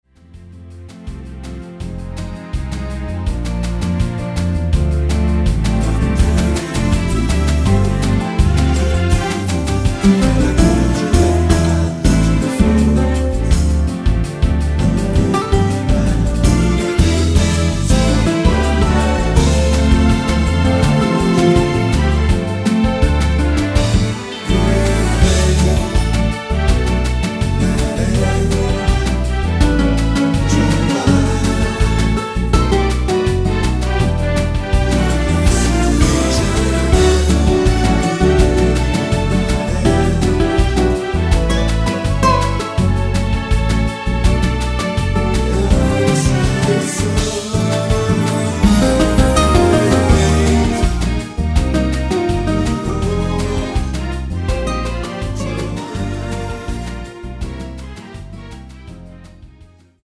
(-1)내린 코러스 포함된 MR 입니다.(미리듣기 참조)
◈ 곡명 옆 (-1)은 반음 내림, (+1)은 반음 올림 입니다.
앞부분30초, 뒷부분30초씩 편집해서 올려 드리고 있습니다.